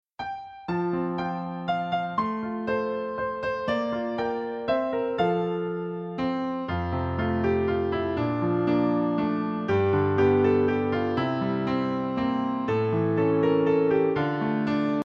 Piano Solo
Listen to a sample of the instrumental song